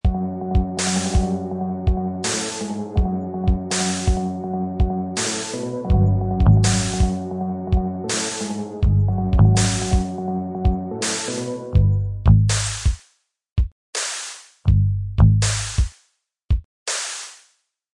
Download Crime scene ambience sound effect for free.
Crime Scene Ambience